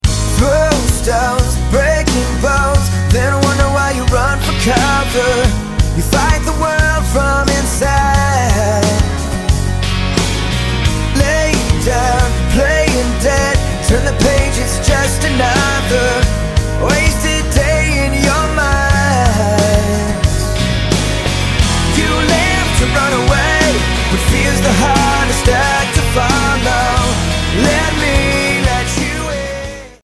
мужской вокал
Pop Rock
Soft rock
ballads
christian rock